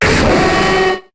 Cri de Démolosse dans Pokémon HOME.